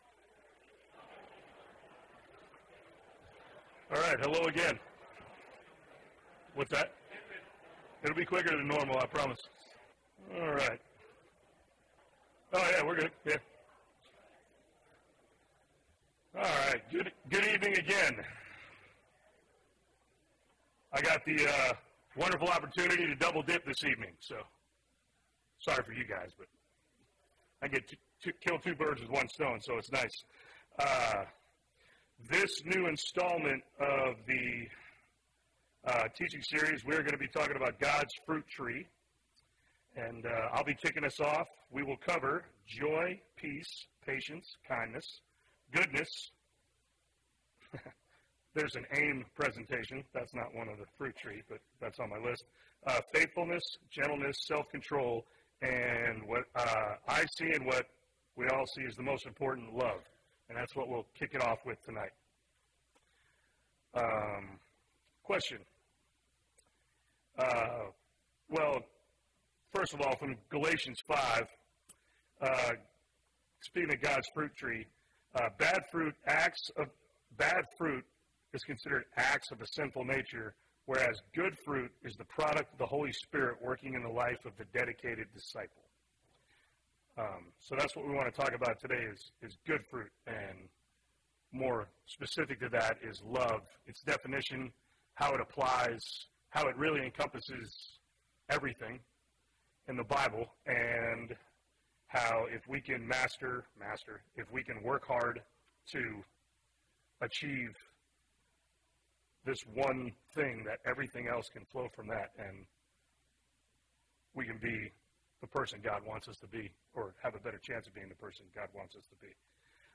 Love (1 of 12) – Bible Lesson Recording